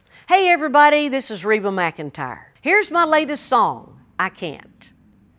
LINER Reba McEntire (I Can't) 3